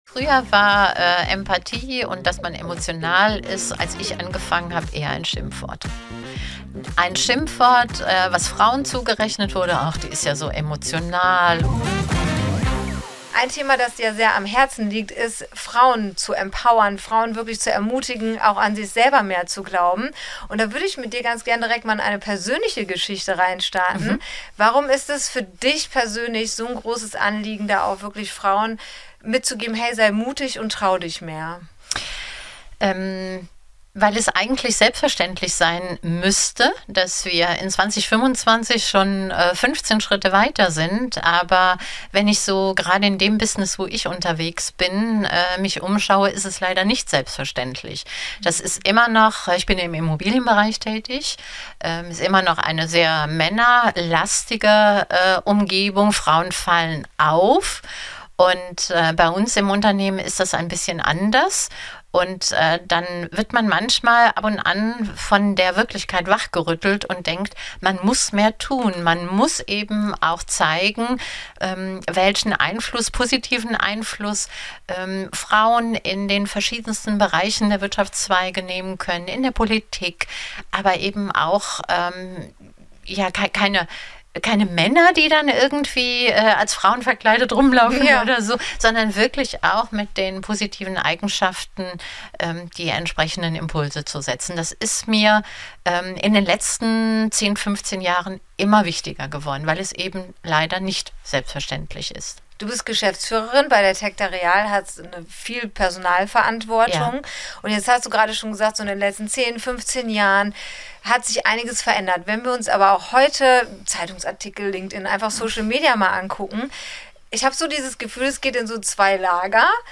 Gedreht haben wir im Eventflugzeug auf dem euronova CAMPUS in Hürth.